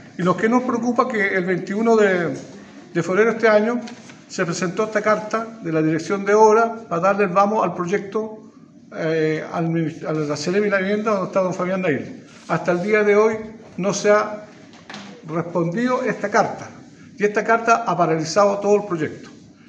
Manuel Rivera, presidente de la Comisión de Vivienda del Consejo Regional, pidió a Nail que defina su postura para evitar retrasos y cumplir con la política de vivienda del presidente Boric.